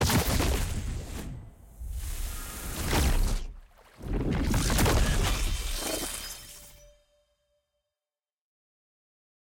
sfx-exalted-rolling-ceremony-multi-anim.ogg